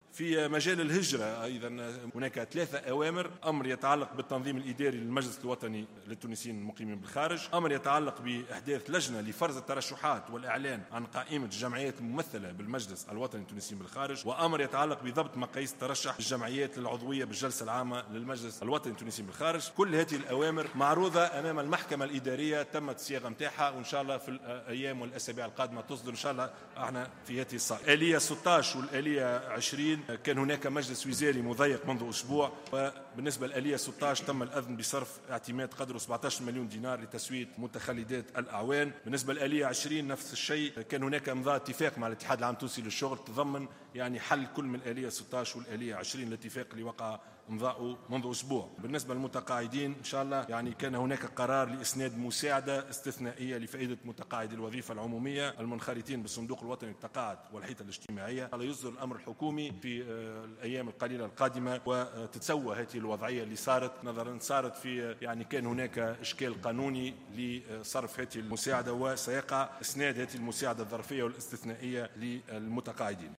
وأضاف على هامش ردّه على اسئلة النواب في جلسة عامة خصّصت لمنح الثقة لوزير الداخلية الجديد، انه كان هناك اشكالا قانونيا لصرف هذه المساعدات بعد اقرارها وسيقع اسنادها بشكل ظرفي واستثنائي للمتقاعدين.